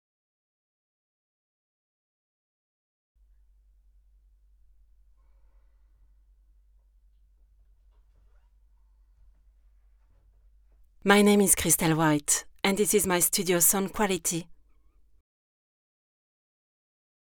Female
Approachable, Assured, Authoritative, Bright, Bubbly, Character, Children, Confident, Conversational, Corporate, Deep, Energetic, Engaging, Friendly, Gravitas, Natural, Reassuring, Smooth, Soft, Versatile, Warm, Witty
Microphone: TLM Neumann 103